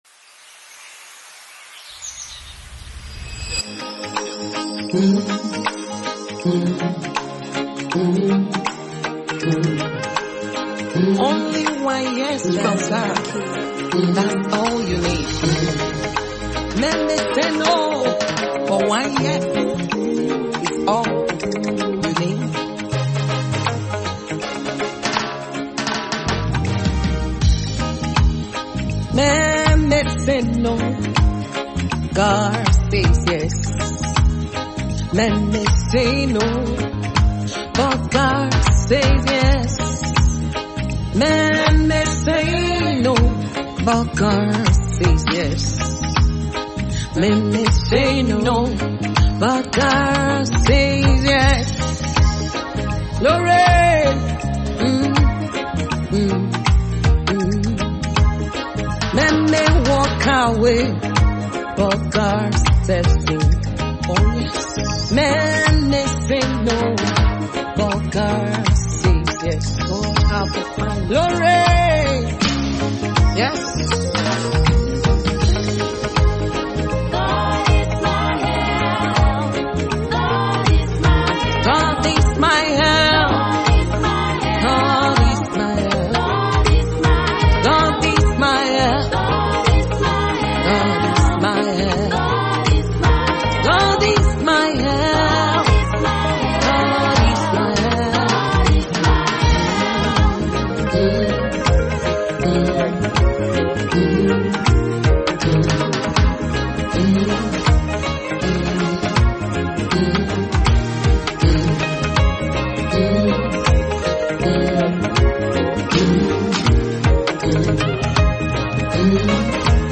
musicWorship
powerful gospel song
With heartfelt lyrics and uplifting melodies